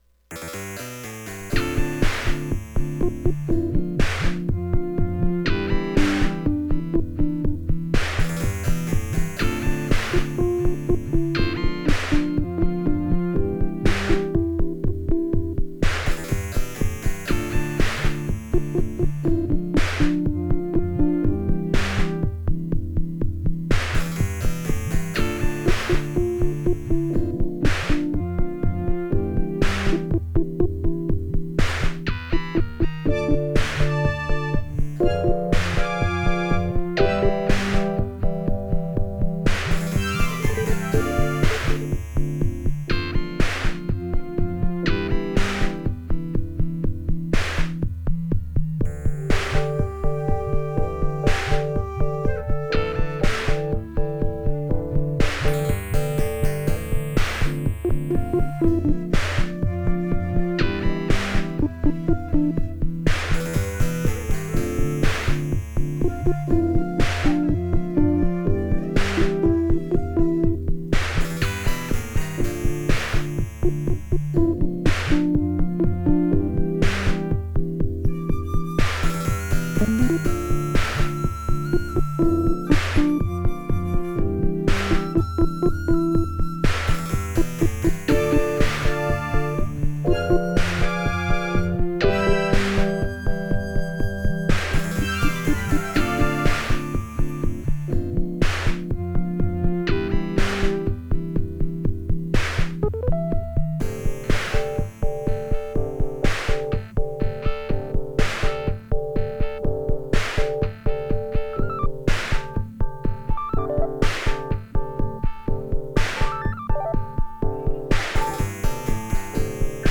Using a late 1970s clock radio, the Radio Ace is a 3 x 8 programmable drum machine that has an analog bass drum, a gated input, and it uses the audio from the radio as the source for the snare drum noise.